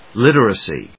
音節lit・er・a・cy 発音記号・読み方
/líṭərəsi(米国英語), ˈlɪtɜ:ʌsi:(英国英語)/